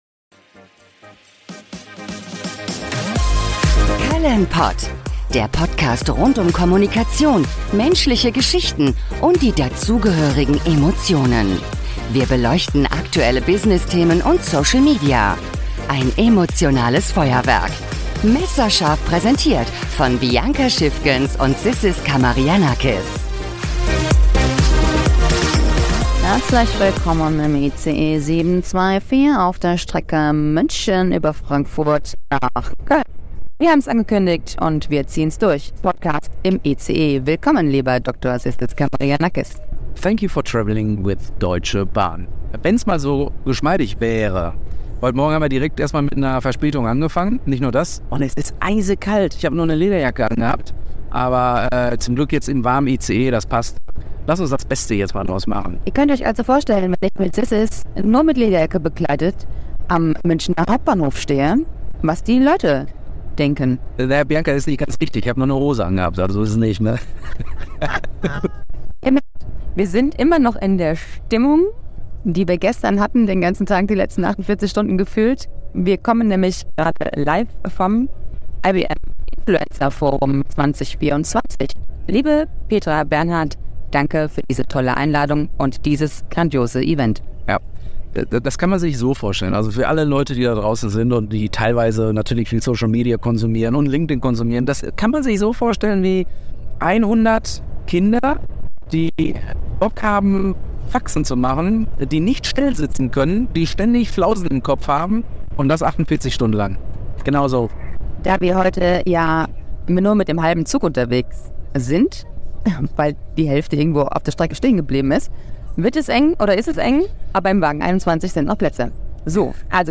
Ein Experiment: Denn wir haben uns getraut, den Podcast im Zug aufzunehmen. Es knackst an der ein oder anderen Stelle und das bitten wir zu entschuldigen.